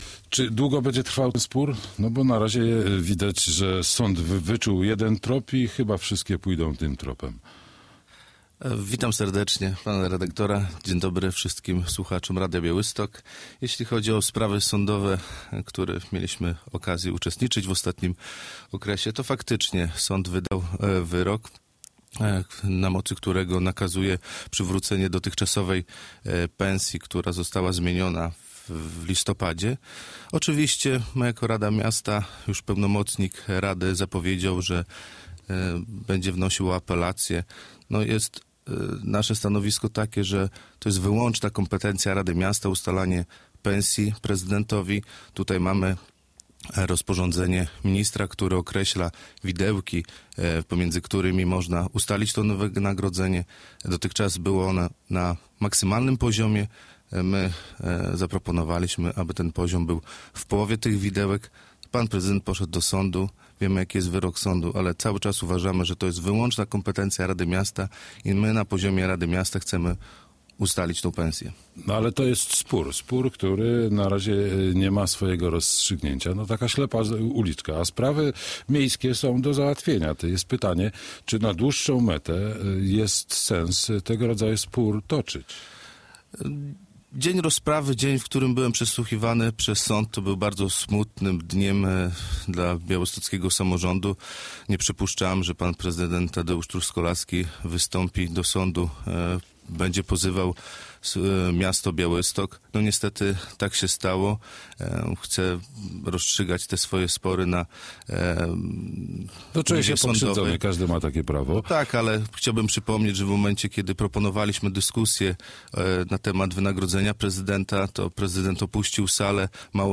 Mariusz Gromko - przewodniczący Rady Miasta w Białymstoku
Radio Białystok | Gość | Mariusz Gromko - przewodniczący Rady Miasta w Białymstoku